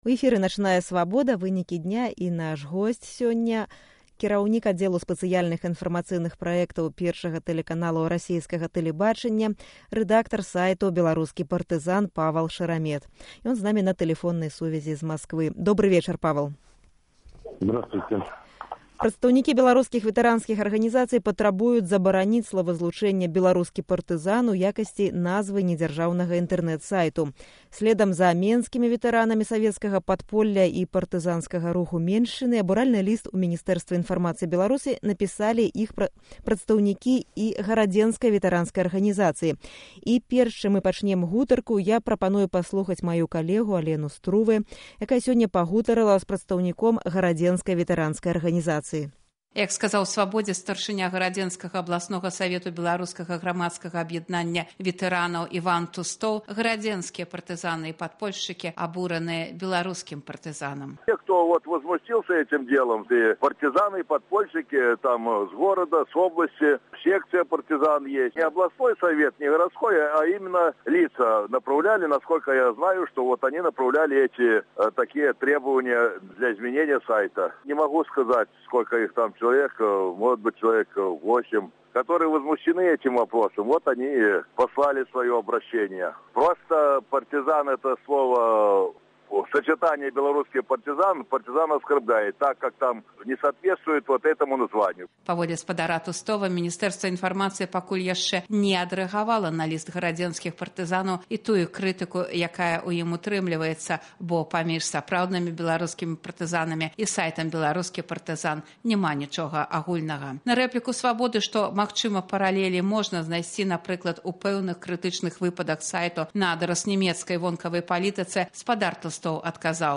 Інтэрвію з Паўлам Шараметам